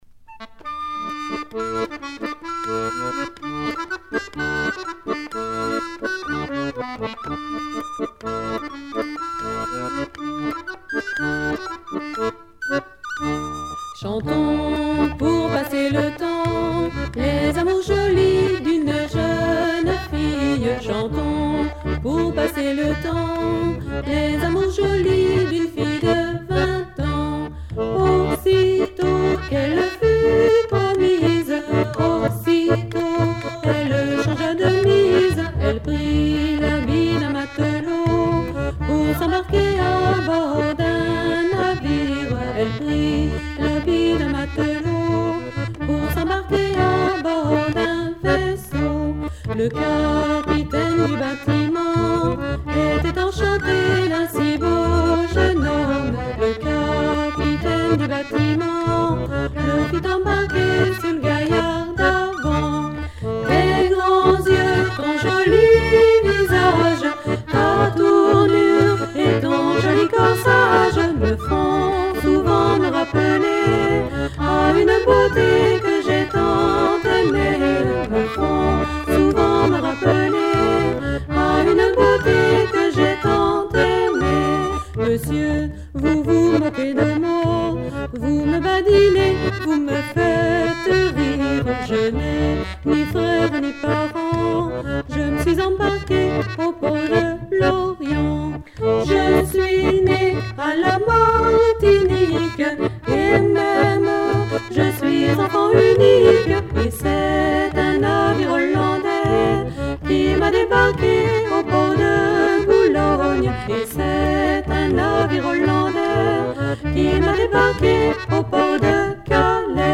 Genre strophique
Chants et musiques traditionnels des milieux maritimes